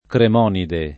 [ krem 0 nide ]